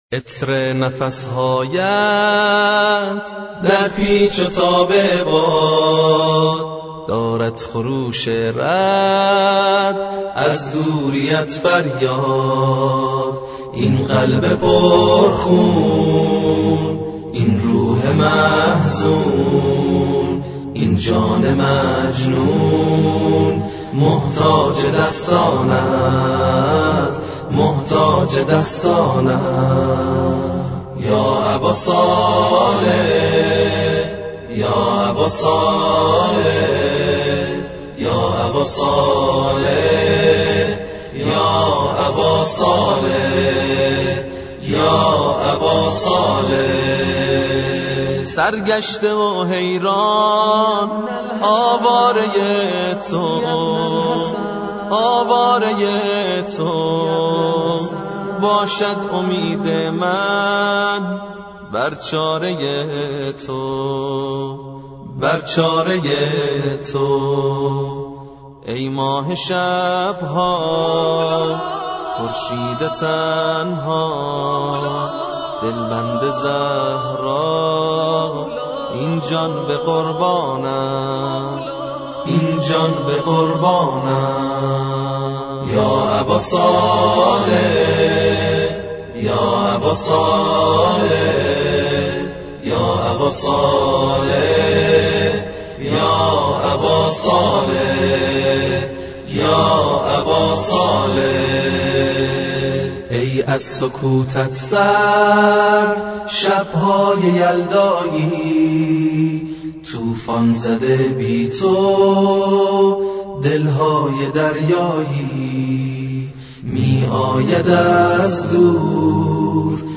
همخوانی مهدوی